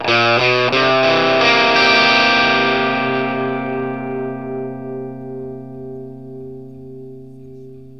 PICK1 C 7 60.wav